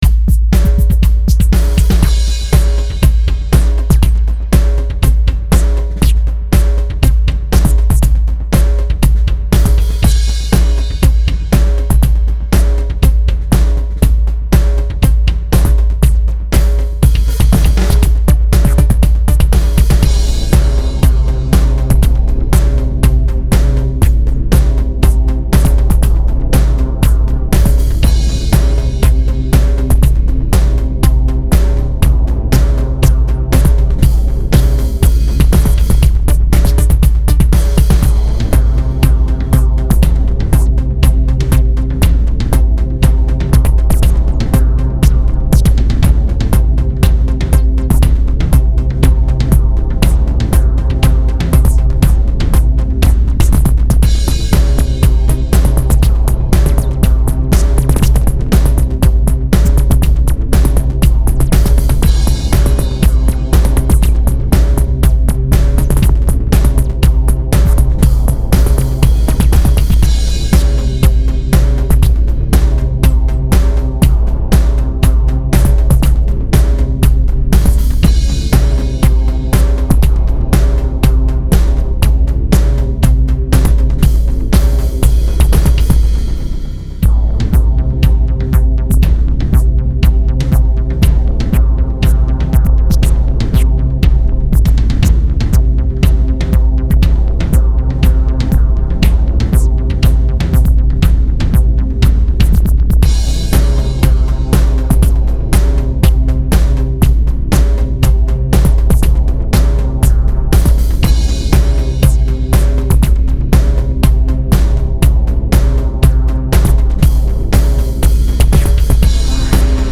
NEW SOUND-MIX